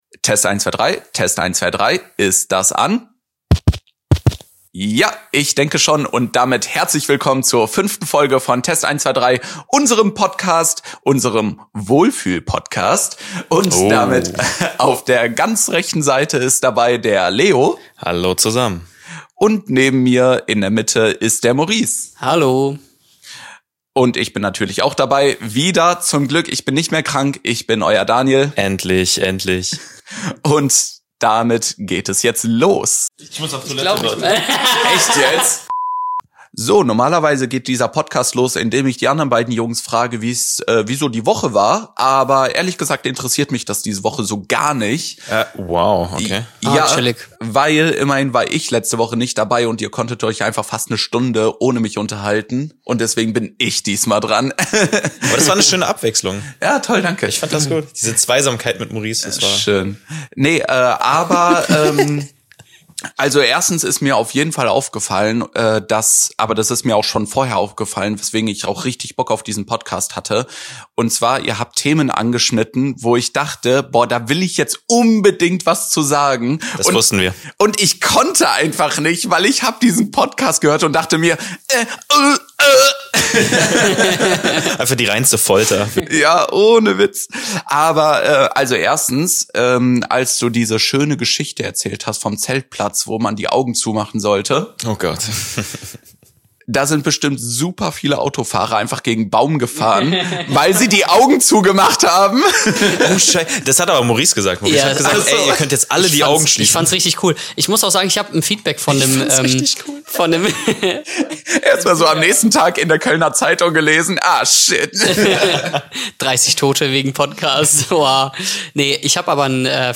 In dieser Folge sind wir endlich wieder zu dritt! Vielleicht genau deshalb gibt es ordentlich Sprachbedarf.